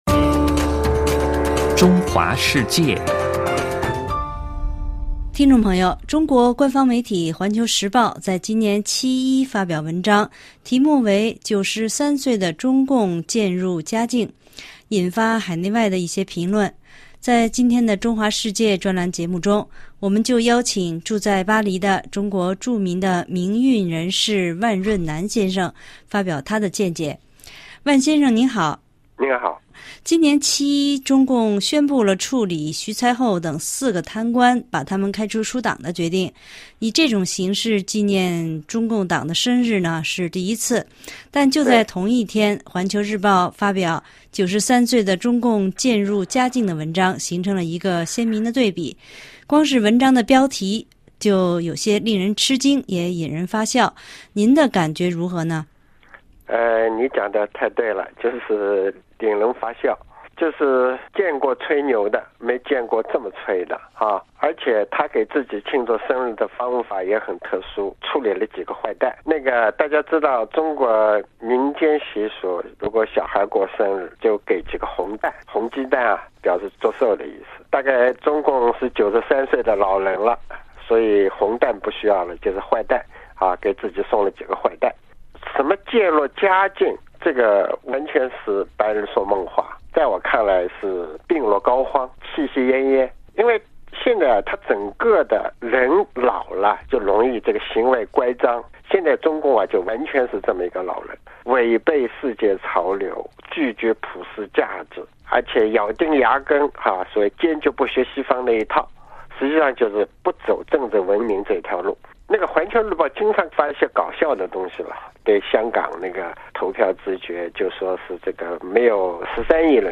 中国官方媒体《环球时报》在今年七一发表文章，题目为“93岁的中共渐入佳境”，引发海内外的一些评论。在今天的《中华世界》专栏节目中，我们邀请住在巴黎的中国著名民运人士万润南先生发表他的见解。